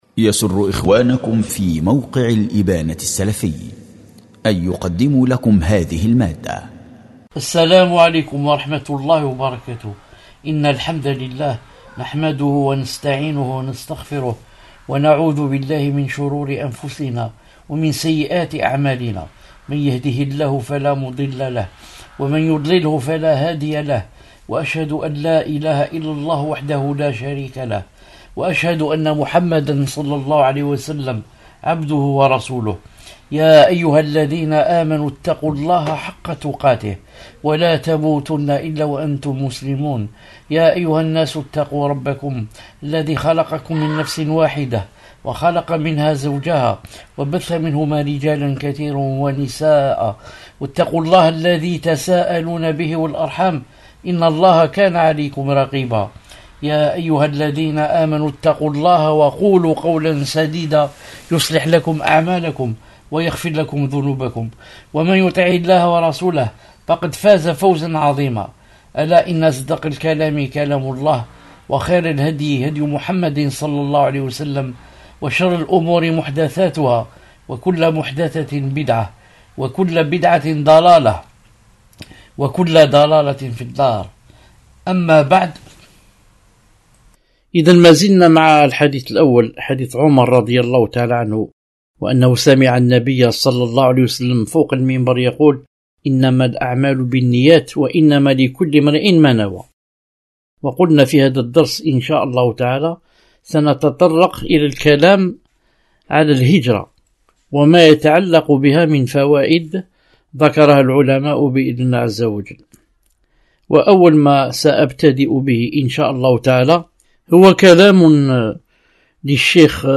شرح الأربعون النووية الدرس 3